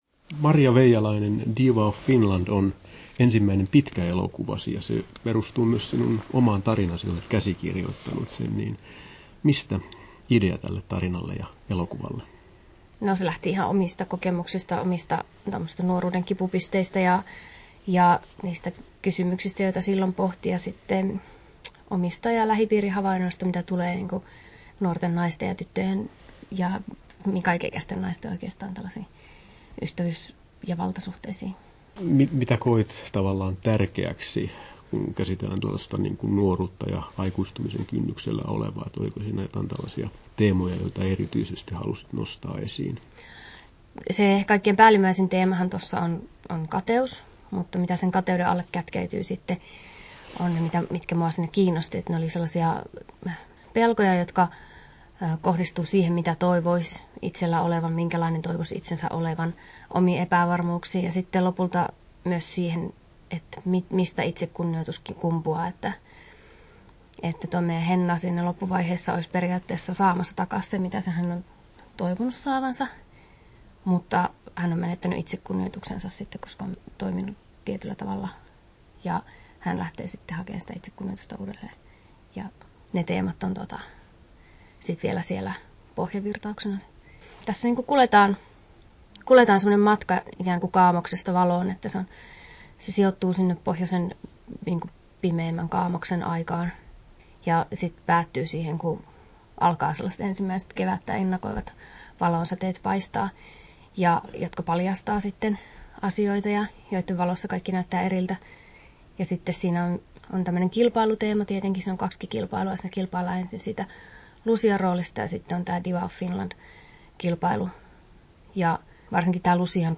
Haastattelut